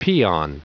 Prononciation du mot peon en anglais (fichier audio)
Prononciation du mot : peon